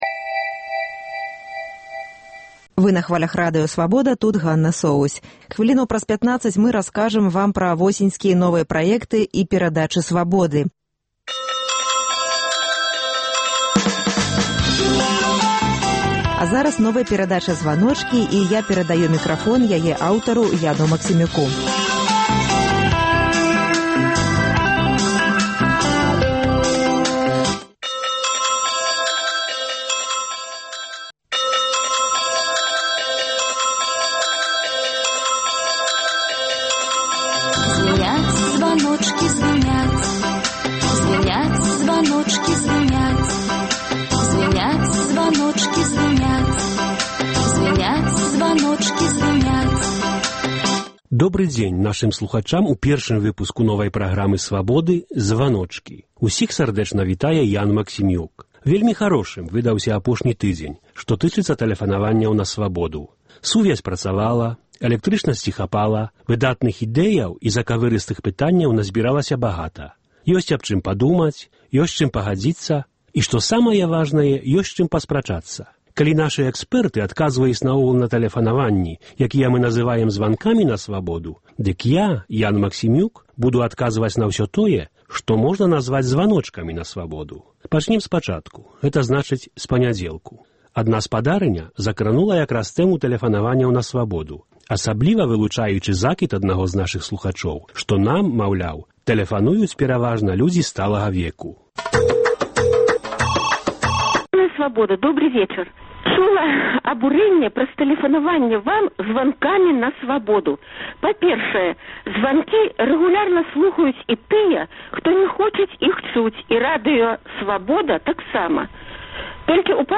Гэта перадача — яшчэ адна магчымасьць для нашай рэдакцыі пагутарыць з нашымі слухачамі, уступіць зь імі ў адмысловую форму дыялёгу. “Званочкі” — гэта дыялёг з тымі слухачамі, якія звоняць на наш аўтаадказьнік у Менску і пакідаюць там свае думкі, прапановы і заўвагі.